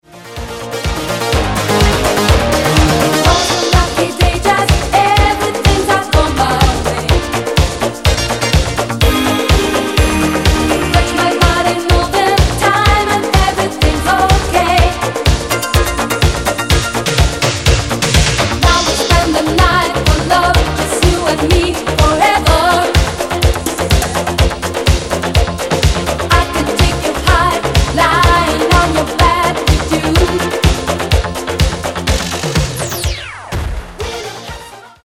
Genere: Disco | Soul | Funky